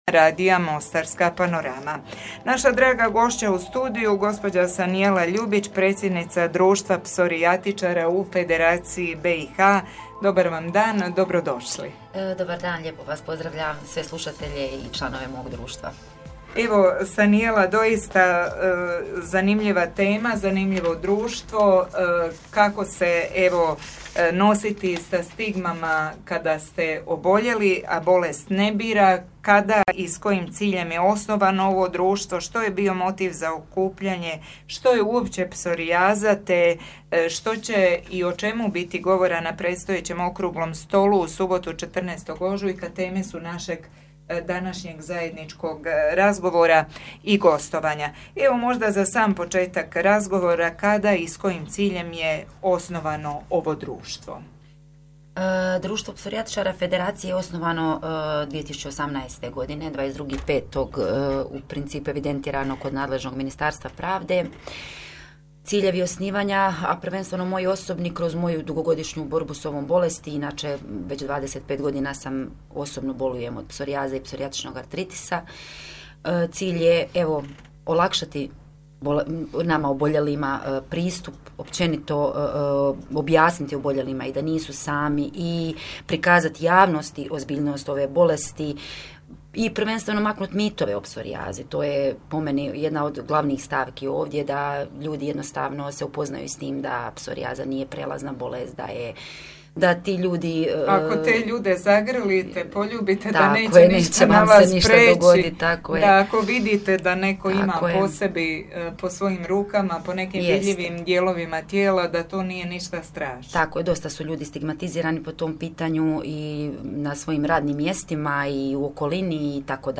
gostovanje_na_radiu_mosstarska_panorama.mp3